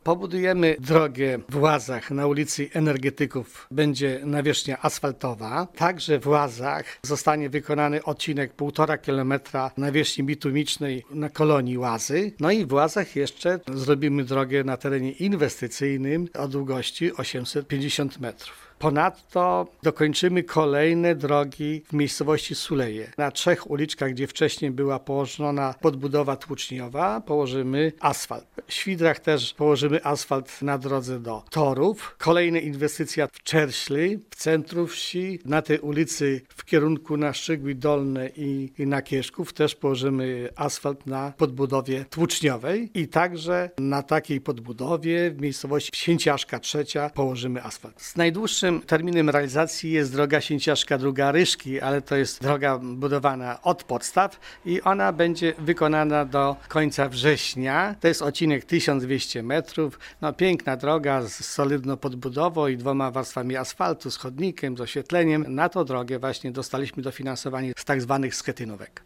Zastępca wójta Wiktor Osik tłumaczy, że trzeba było je rozłożyć w czasie, bo gmina nie mogłaby sfinansować budowy większej ilości dróg w pierwszym pierwszym półroczu. Wiktor Osik zapewnia jednak, że już w czerwcu będzie ogłoszony przetarg na inwestycje, które zostaną wykonane najpóźniej do września: